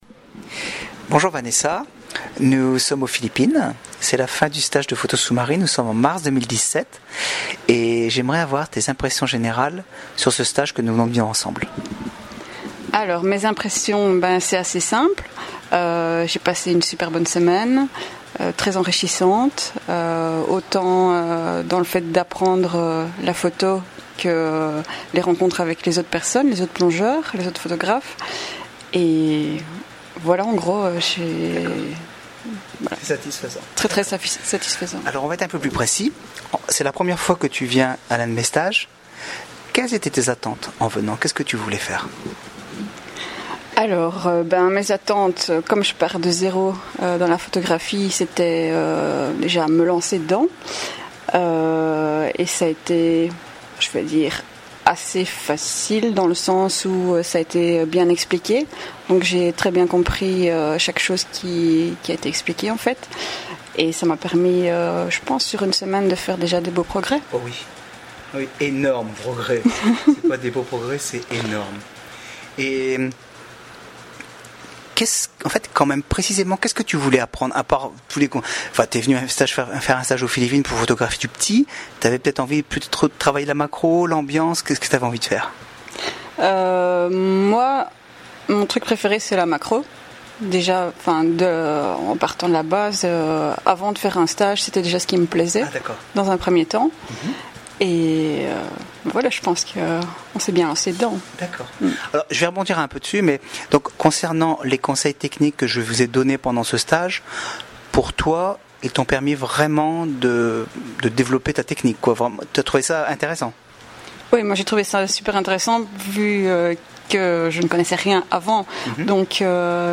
Témoignages écrits et oraux des participants